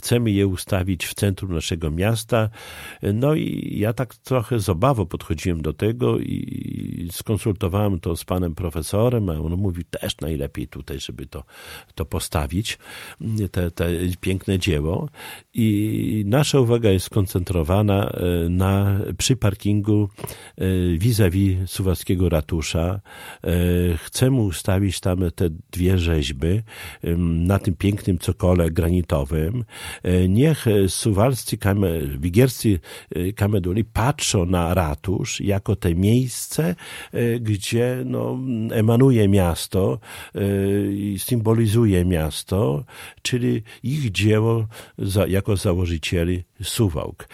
Już wiadomo, że rzeźby zostaną ustawione na południowym krańcu Parku Konstytucji 3 Maja, przy parkingu vis-a-vis magistratu. Szczegóły przedstawił w piątek (13.10) w Radiu 5 Czesław Renkiewicz, prezydent Suwałk.